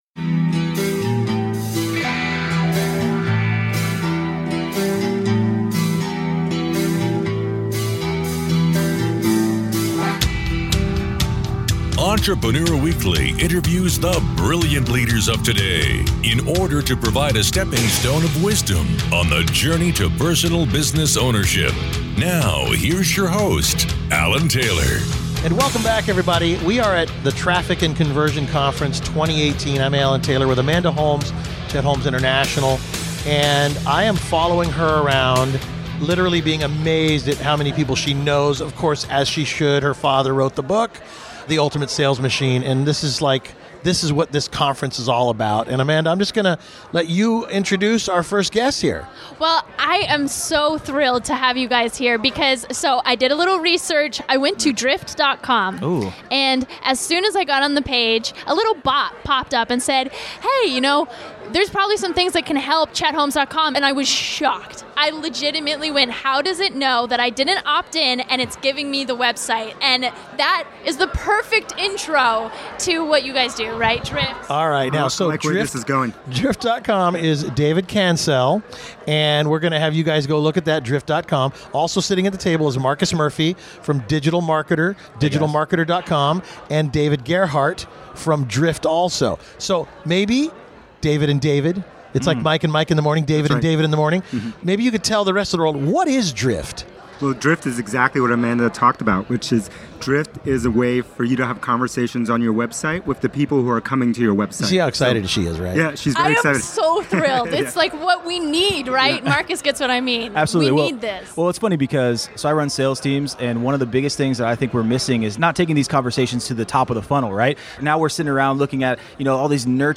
Attention Baby Boomers: Don't get left behind with antiquated marketing campaigns and sales conversion funnels. Join us for part one of our special event coverage from the Traffic & Conversion Summit 2018.